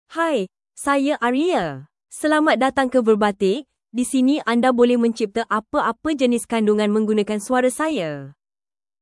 Aria — Female Malay (Malaysia) AI Voice | TTS, Voice Cloning & Video | Verbatik AI
Aria is a female AI voice for Malay (Malaysia).
Voice sample
Listen to Aria's female Malay voice.
Aria delivers clear pronunciation with authentic Malaysia Malay intonation, making your content sound professionally produced.